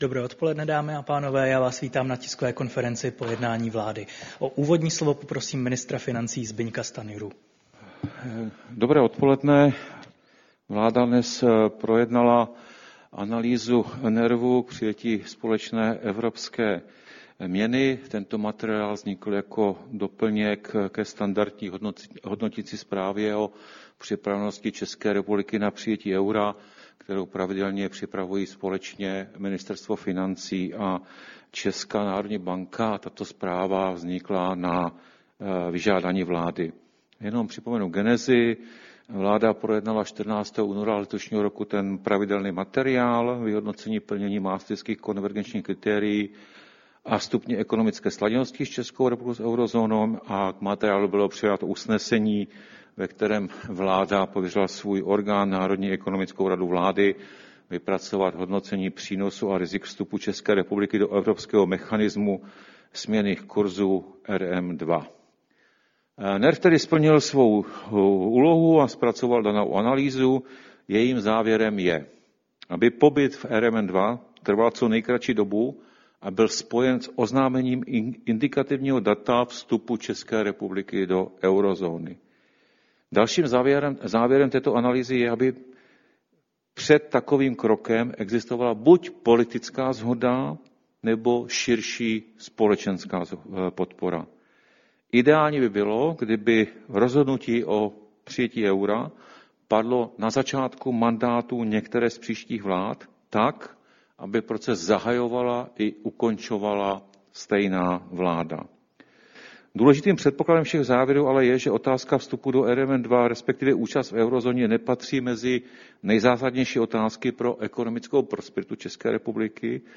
Tisková konference po jednání vlády, 27. listopadu 2024